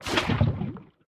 empty_lava2.ogg